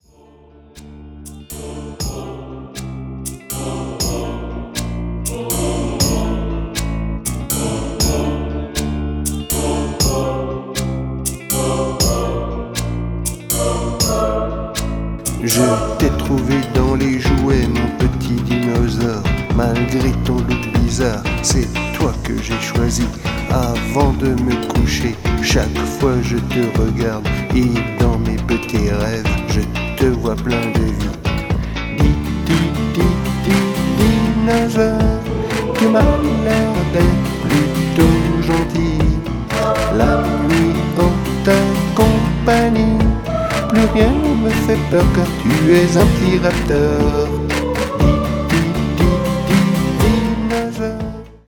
Version vocale